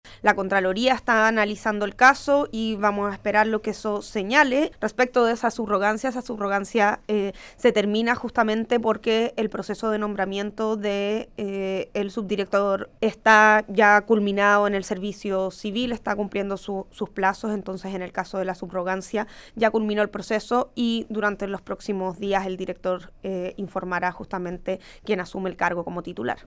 En entrevista con Radio Bío Bío, la secretaria de estado se refirió a la polémica evitando emitir un juicio sobre si es un caso cuestionable, remitiéndose a confirmar que la subrogación de Oñate está llegando a su fin, habiendo finalizado un concurso de Alta Dirección Pública que definirá a quien ocupará el cargo como titular.